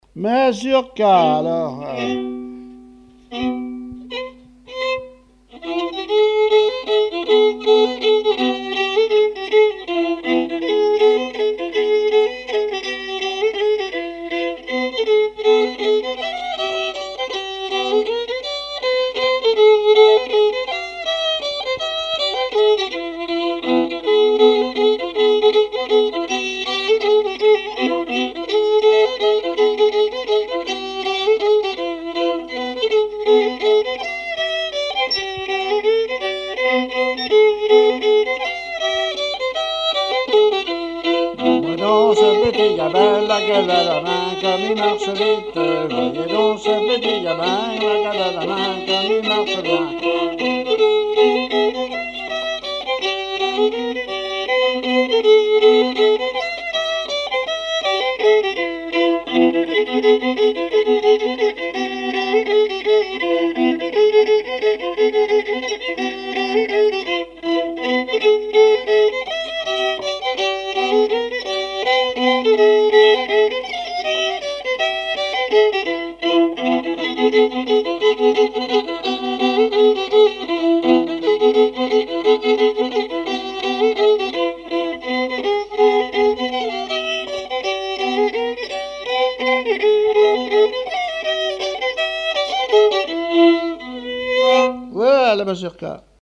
danse : mazurka
répertoire musical au violon
Pièce musicale inédite